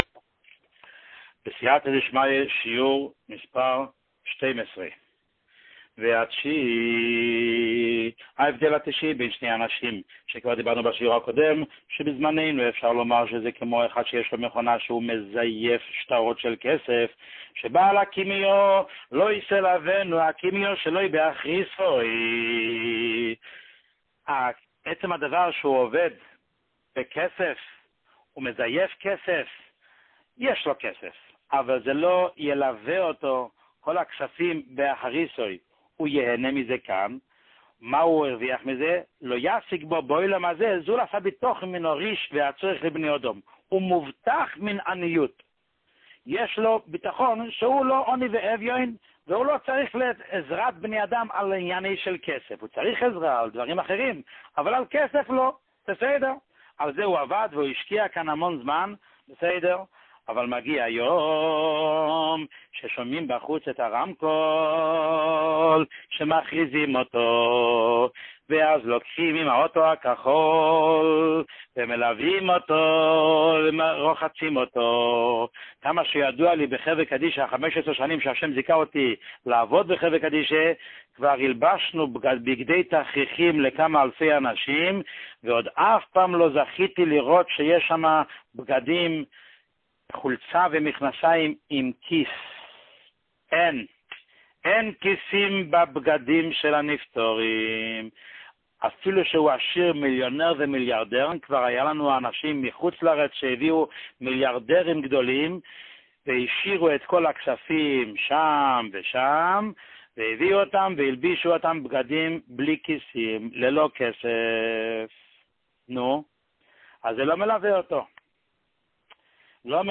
שיעורים מיוחדים